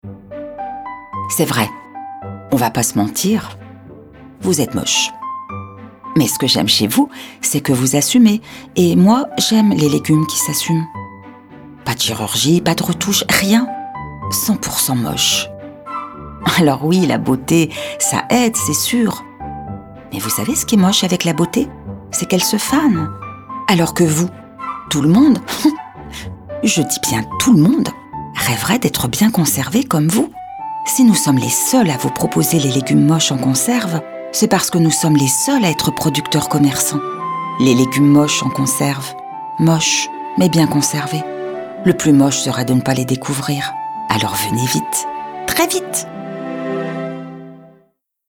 Audio-description